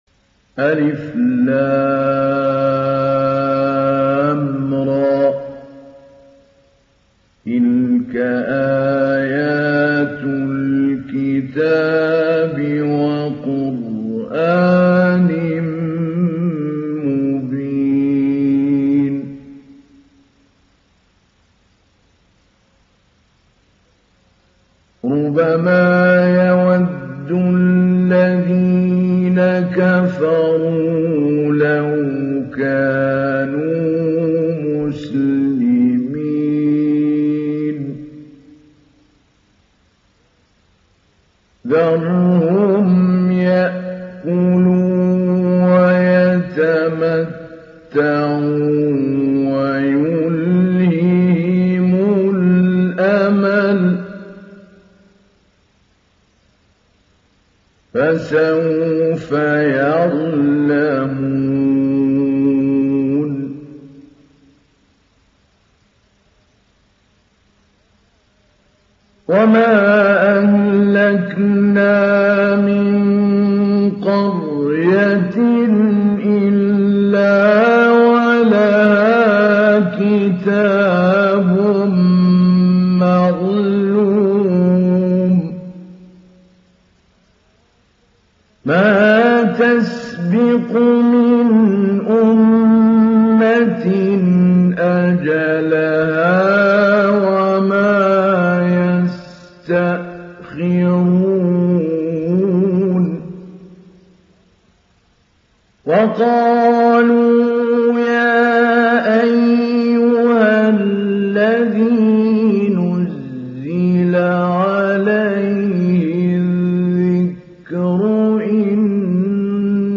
ডাউনলোড সূরা আল-হিজর Mahmoud Ali Albanna Mujawwad